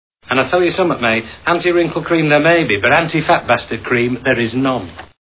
Tags: The Full Monty movie clips The Full Monty The Full Monty clips The Full Monty movie Comedy